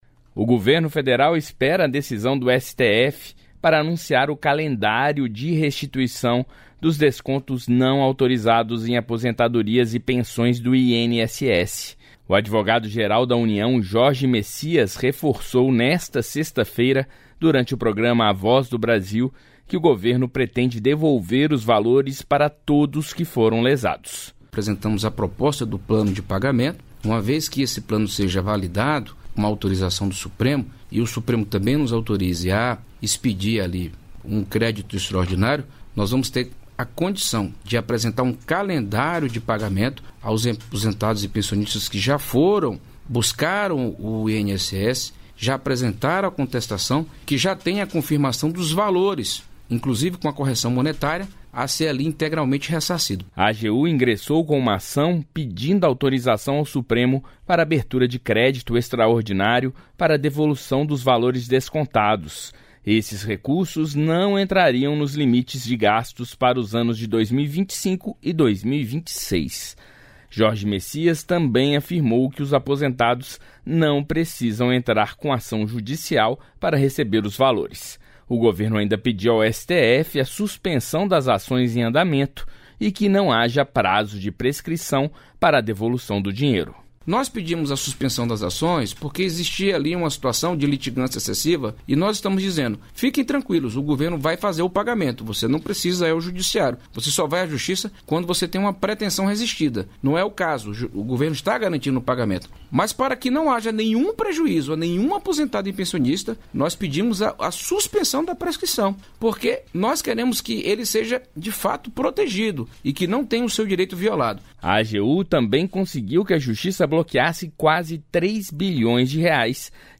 O advogado-geral da União, Jorge Messias, reforçou, nesta sexta-feira (13), durante o Programa A Voz do Brasil, que o governo pretende devolver os valores para todos que foram lesados.